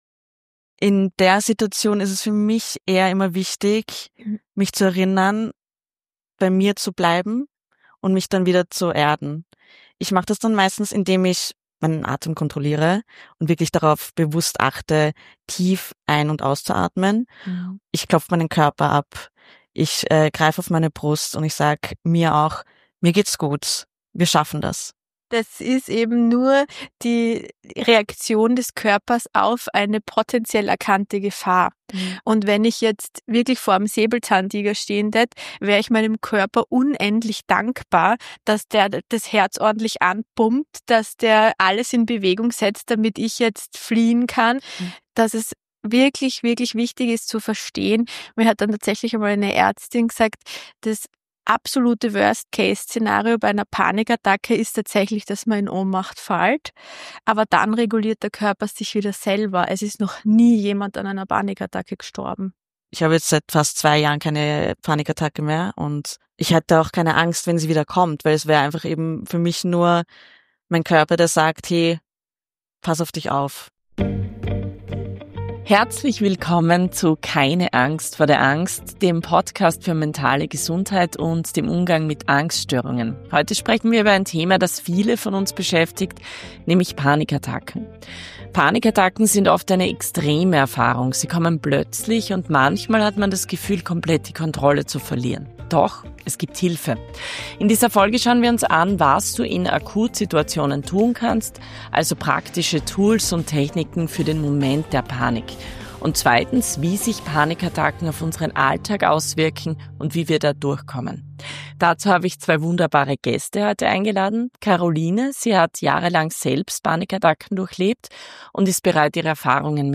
zwei Gesprächspartnerinnen eingeladen